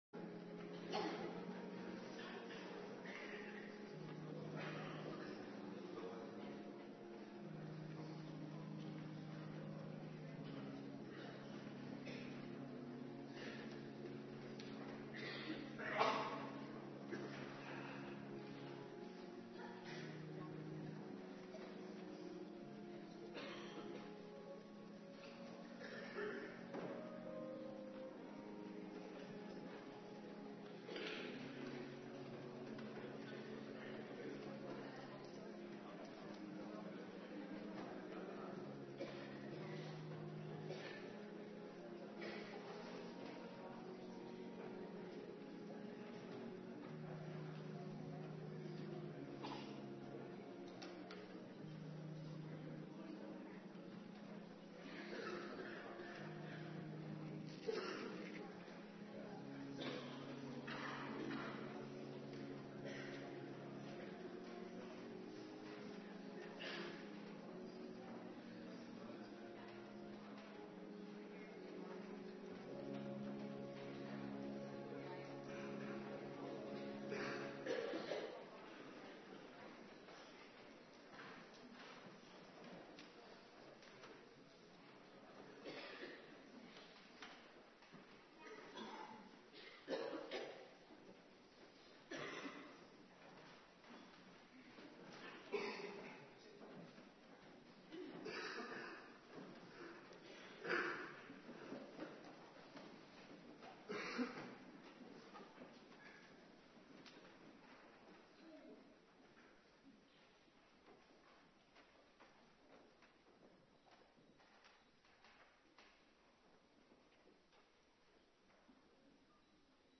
Morgendienst
09:30 t/m 11:00 Locatie: Hervormde Gemeente Waarder Agenda: Kerkdiensten Terugluisteren Handelingen 1:4-14